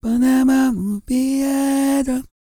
E-CROON 3045.wav